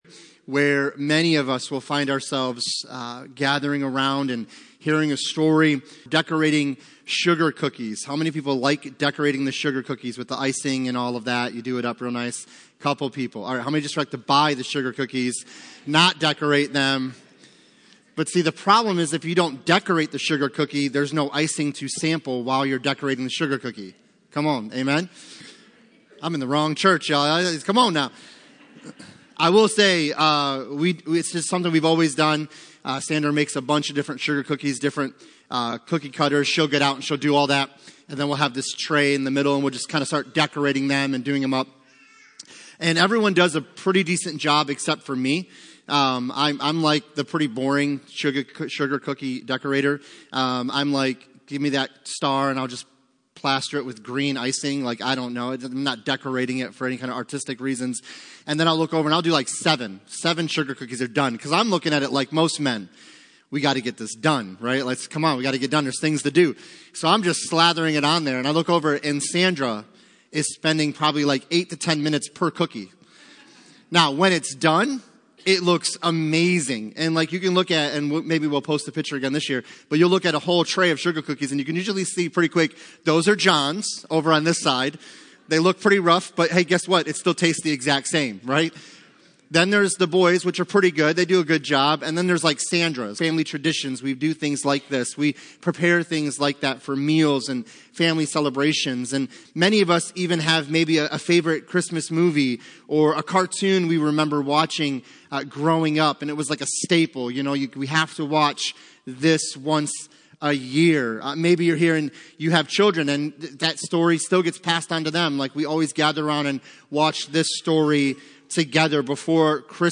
Passage: Isaiah 9:6-7 Service Type: Sunday Morning